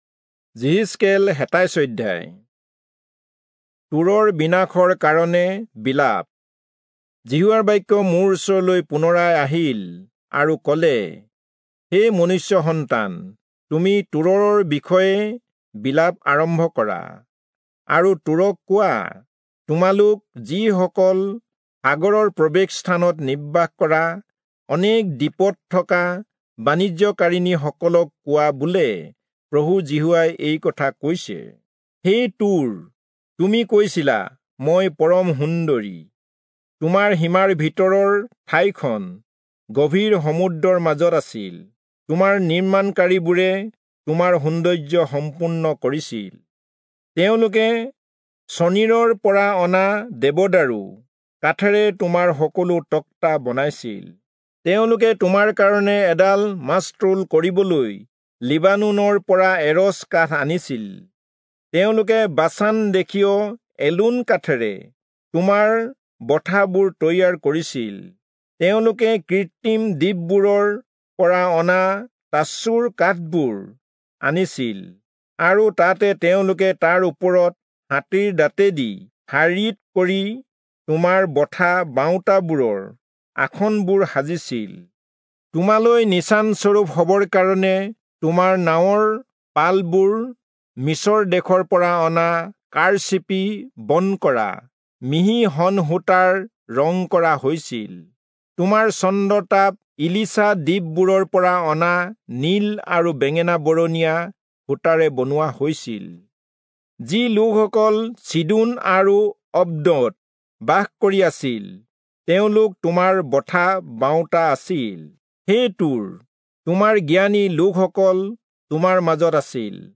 Assamese Audio Bible - Ezekiel 42 in Irvas bible version